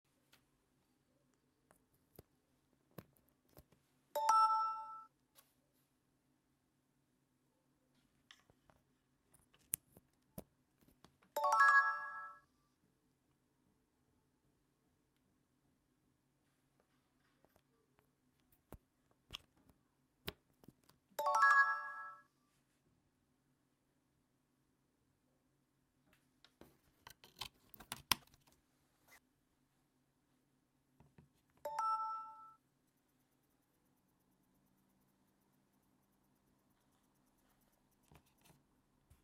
SAMSUNG ONE UI 7 ŞARJ sound effects free download
(ONE UI7 CHARGE SOUND EFFECT)